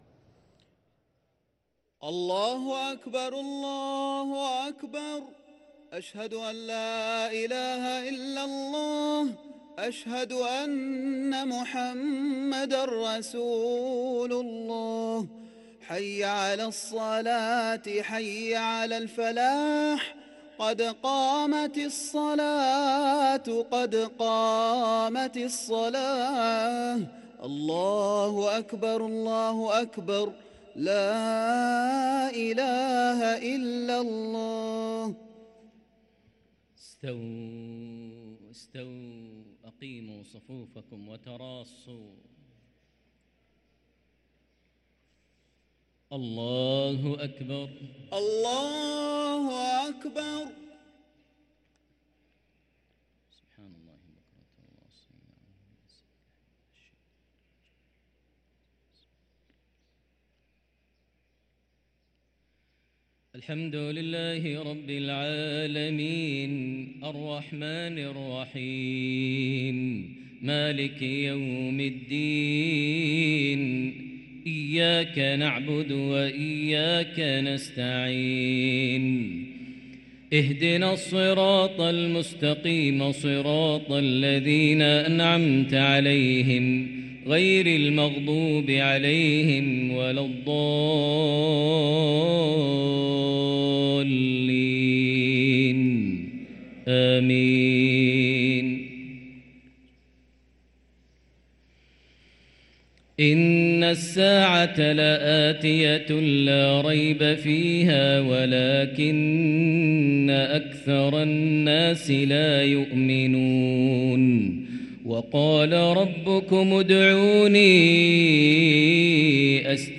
صلاة المغرب للقارئ ماهر المعيقلي 8 رجب 1444 هـ
تِلَاوَات الْحَرَمَيْن .